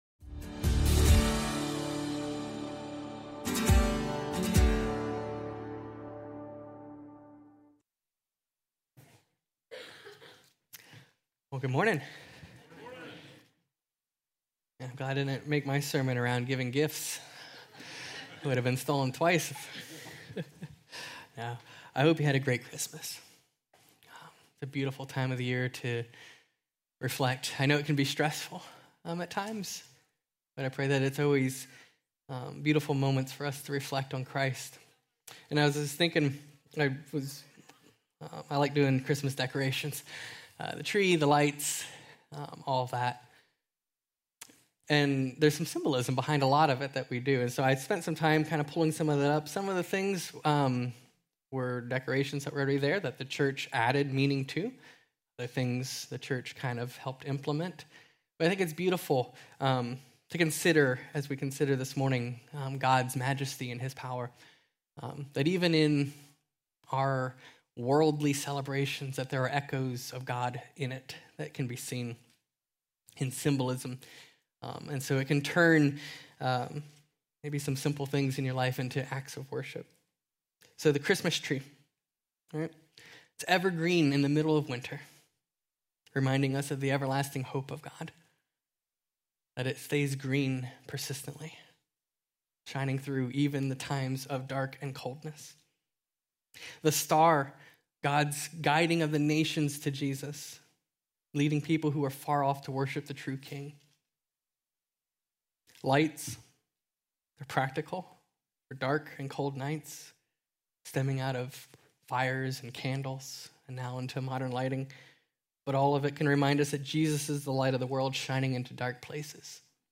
Stand Alone Message